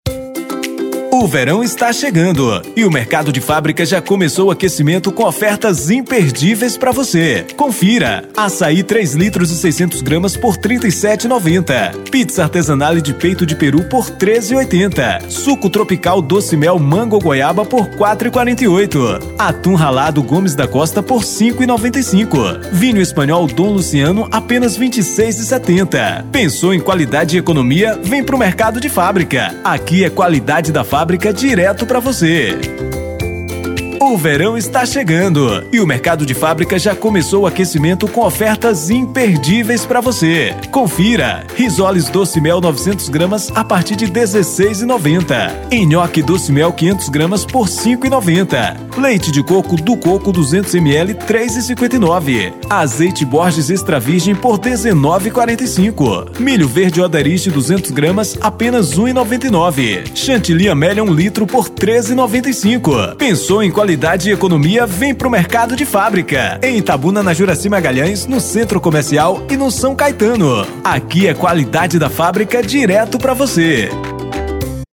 MERCADO-D´FABRICA-CARRO-DE-SOM-ITABUNA-ATE-15-11.mp3